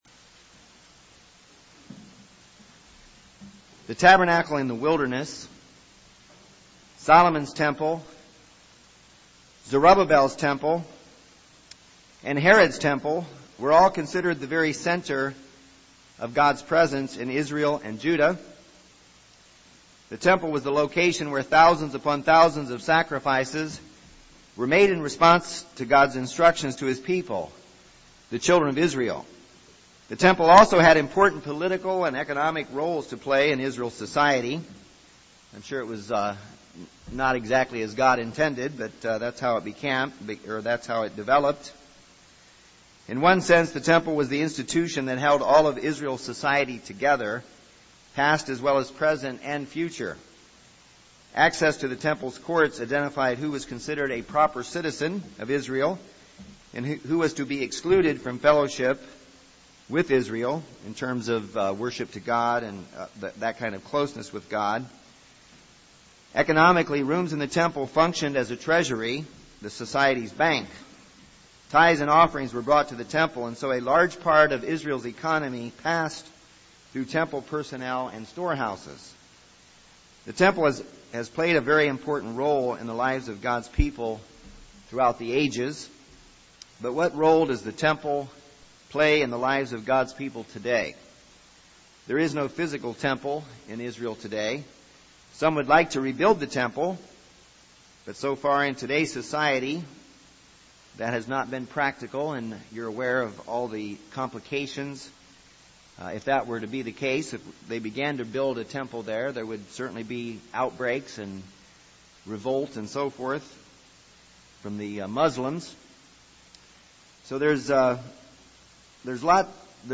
This sermon discusses seven principles to consider in regard to the building of God’s spiritual temple.